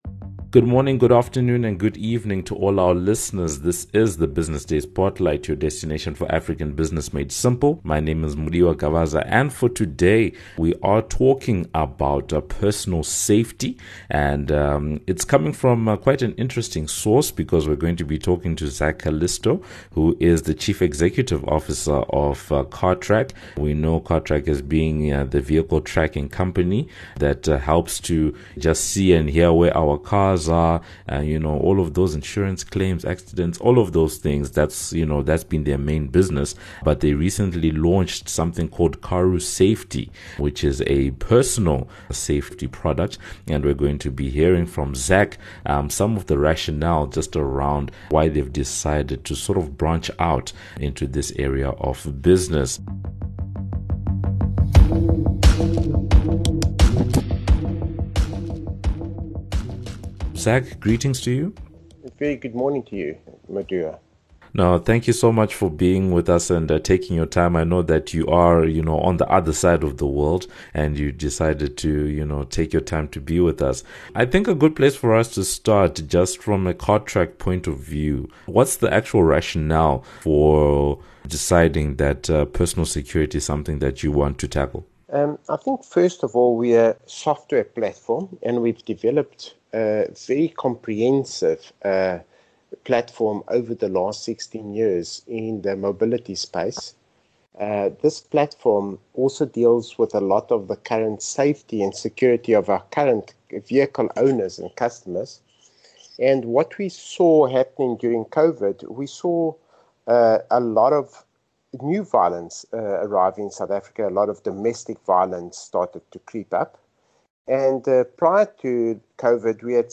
The conversation focuses on Cartrack’s rationale for creating the new Karu Safety app, statistics and figures around the opportunity that Cartrack is looking to address with the product, thoughts around how both personal and vehicle security have been affected by Covid-19 and an outlook for the service.